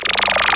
Amiga 8-bit Sampled Voice